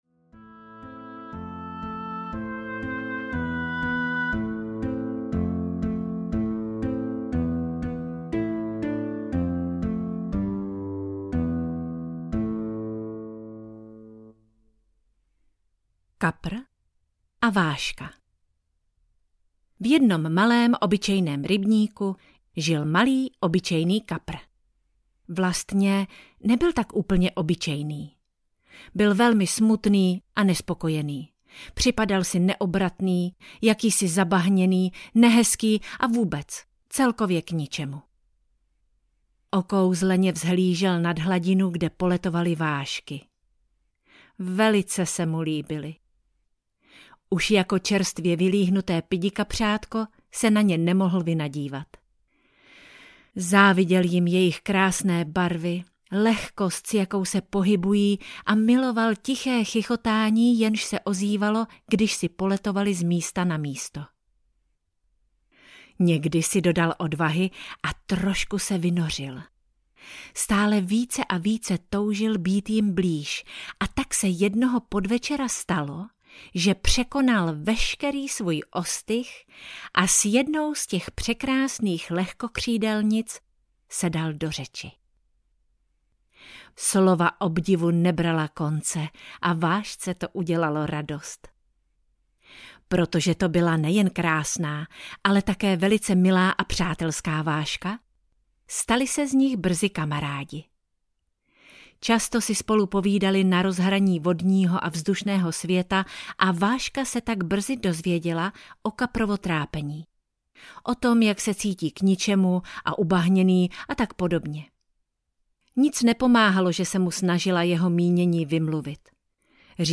Nebeský lívanec audiokniha
Ukázka z knihy